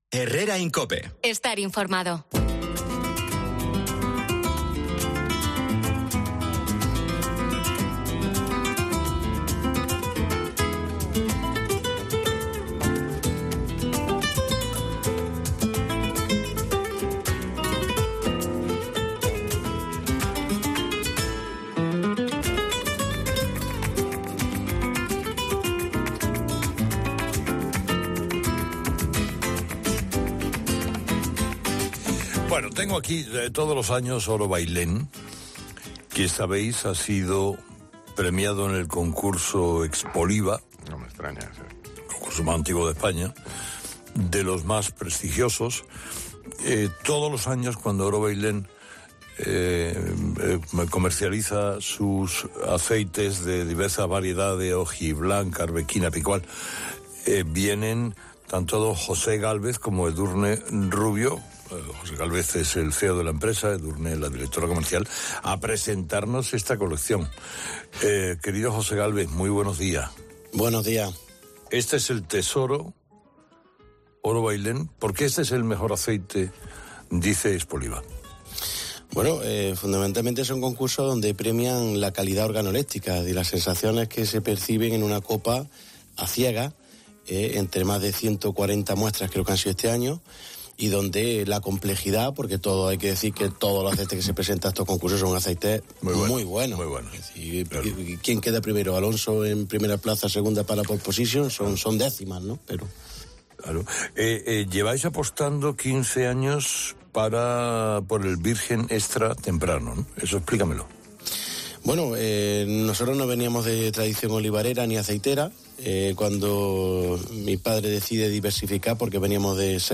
Oro Bailén se encuentra en expansión y cuenta con una presencia en 45 países. Para profundizar en su producto puedes escuchar la entrevista de la mano de Carlos Herrera.